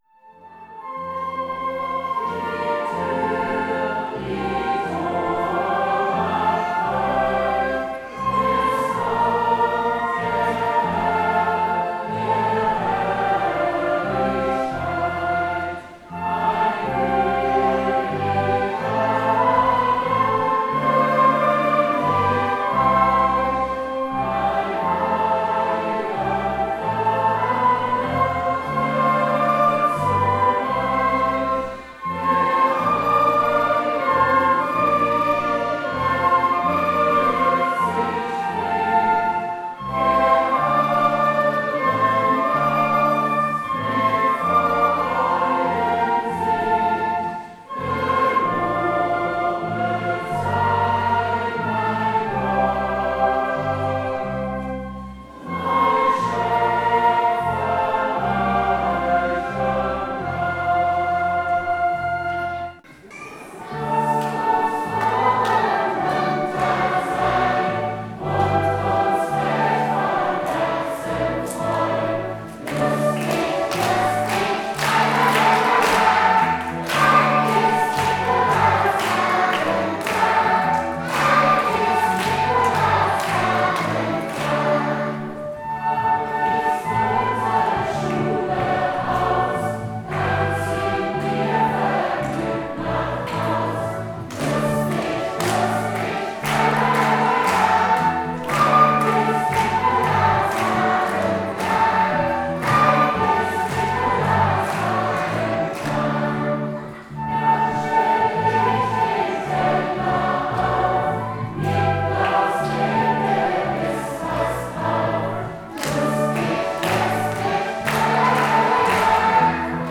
Unser diesjähriges offenes Weihnachtssingen besuchten viele junge Familien.
So wechselten sie mit unserer hauseigenen Combo ab.
Die Eltern ließen sich dabei von ihr animieren, das Meckern der Ziegen und Blöken der Schafe zu imitieren, ein besonderer Spaß.
Es war wieder ein ganz stimmungsvoller musikalischer Adventsnachmittag für die Gemeinde.
offenes-weihnachtssingen-2019-ausschnitte.mp3